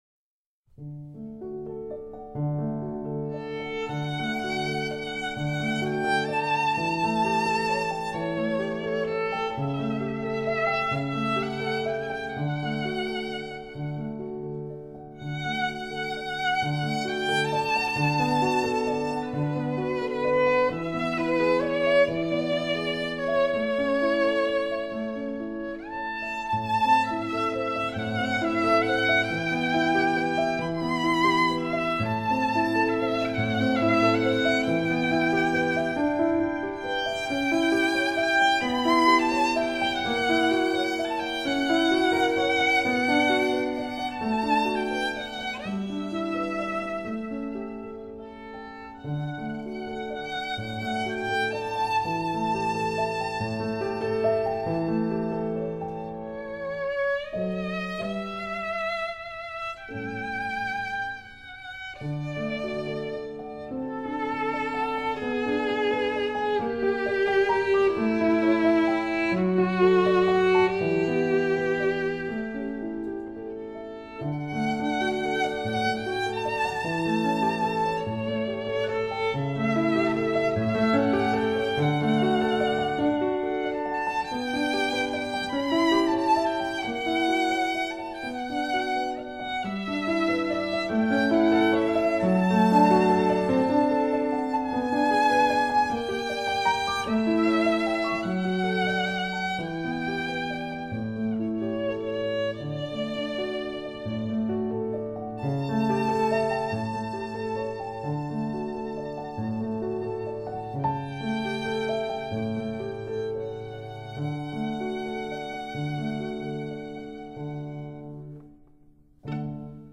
而声音效果更是比上一只唱片有很大的提升。